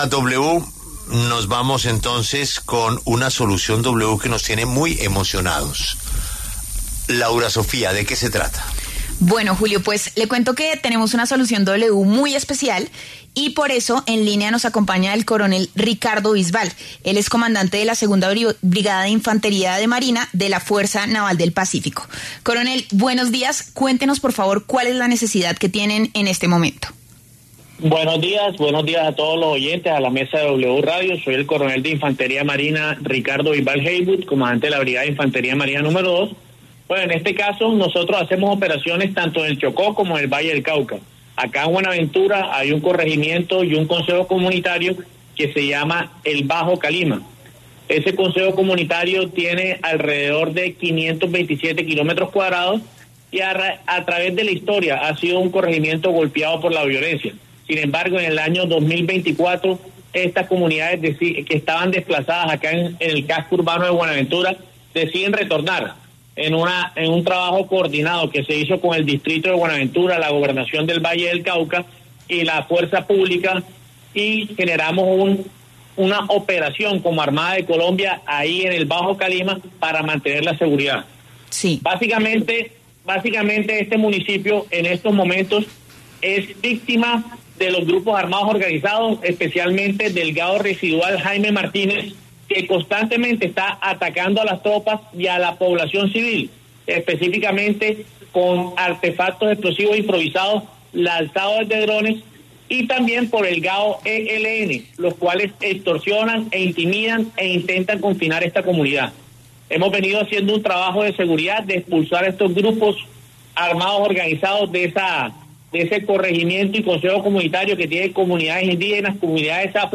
“Sin ellos no llegamos hasta allá”, se dijo al aire, reconociendo el papel clave de la Fuerza Pública no solo en la seguridad, sino en hacer posible que la ayuda llegue.